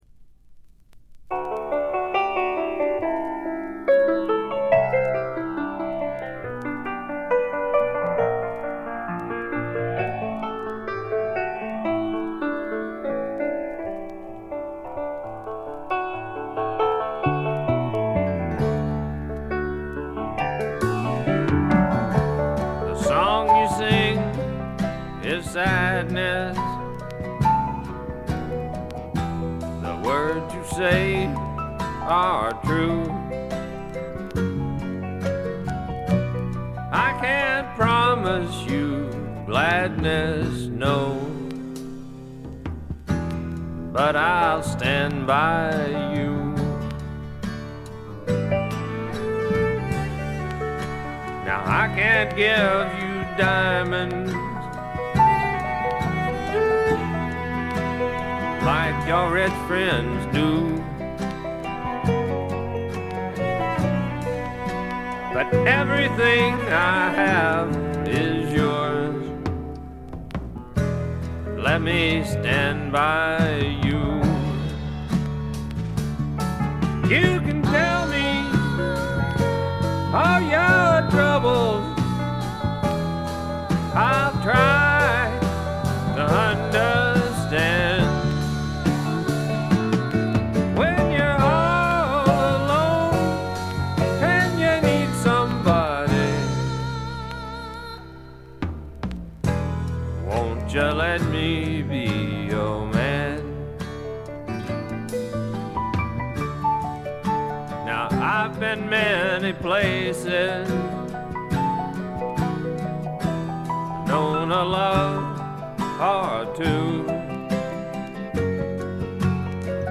静音部で軽微なチリプチ。
いかにもテキサス／ダラス録音らしいカントリー系のシンガー・ソングライター作品快作です。
ヴォーカルはコクがあって味わい深いもので、ハマる人も多いと思います。
試聴曲は現品からの取り込み音源です。